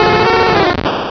pokeemerald / sound / direct_sound_samples / cries / vulpix.aif
-Replaced the Gen. 1 to 3 cries with BW2 rips.